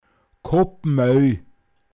pinzgauer mundart
grobes Mehl (fällt am Beginn des Mahlens an) Koppmäi, n.